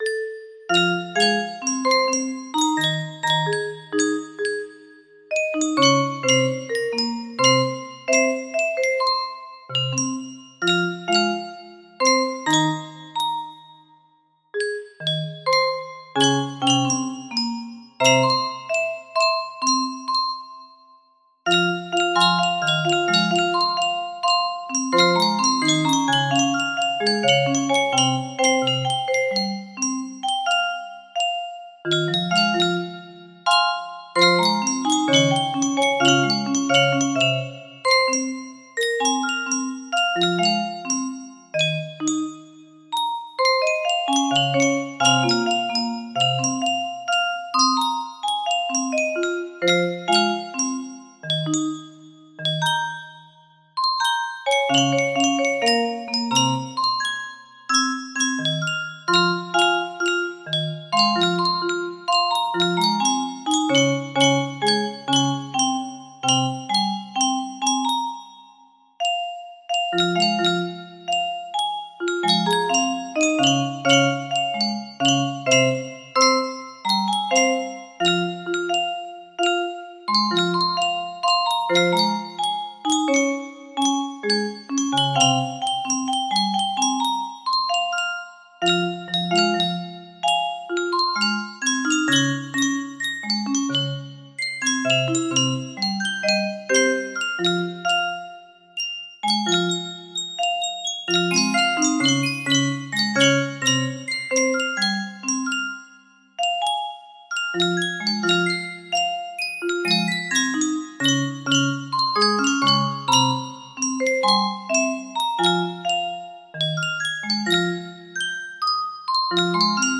Full range 60
Adjusted the tempo for music box, no reds, Enjoy!